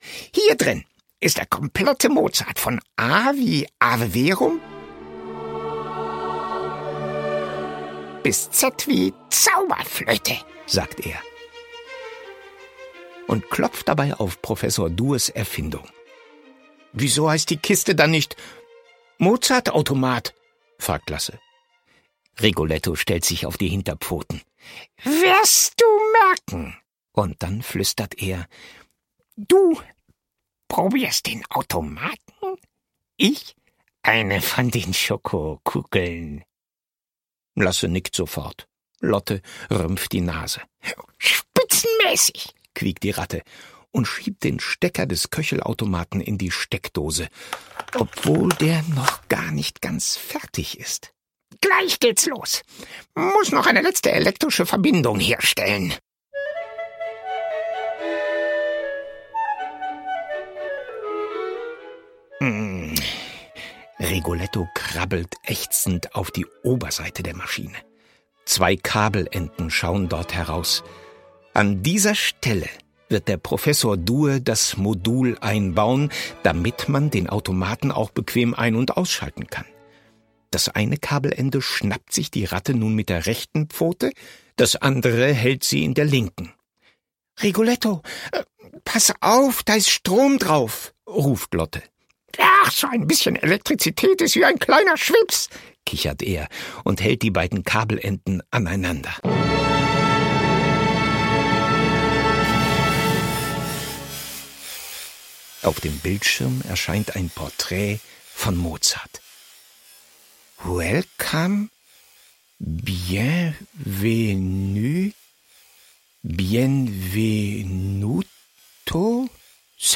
Schlagworte Hörbuch; Lesung für Kinder/Jugendliche • Klassik für Kinder • Klassische Musik • Mozart • Mozart, Wolfgang Amadeus; Kindersachbuch/Jugendsachbuch